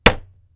Cabinet Door Slam Wooden Sound
household